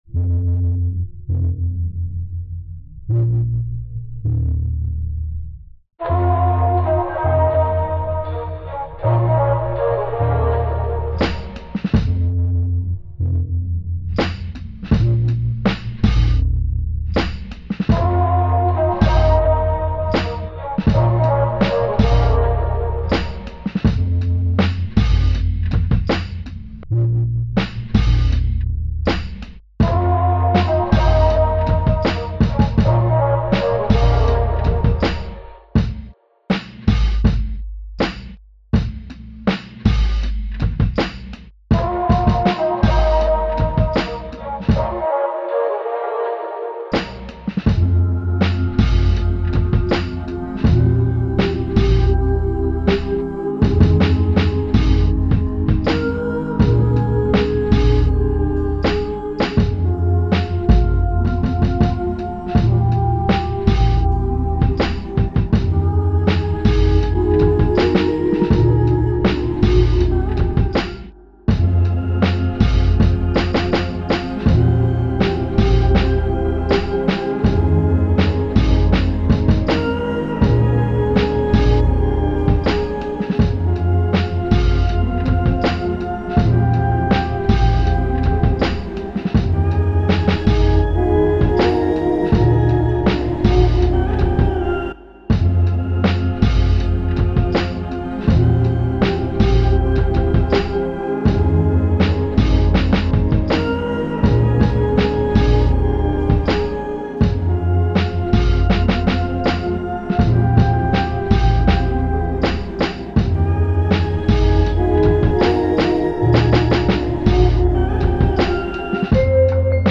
No scratching at all.